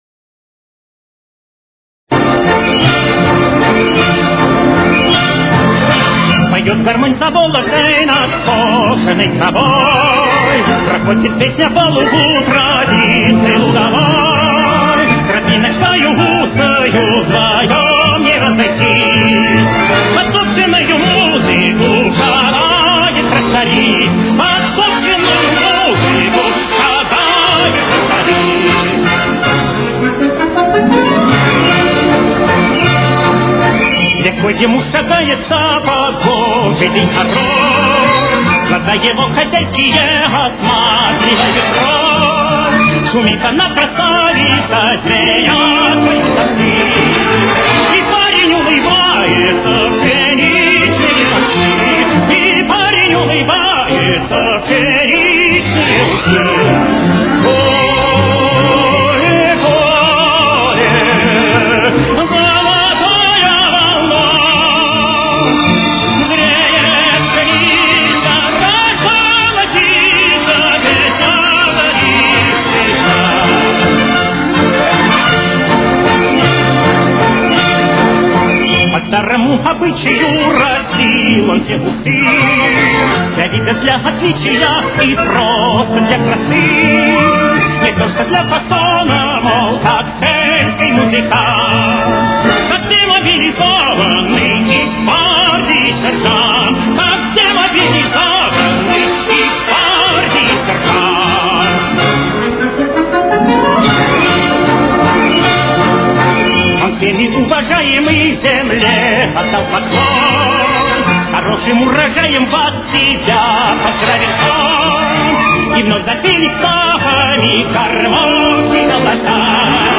Фа мажор. Темп: 164.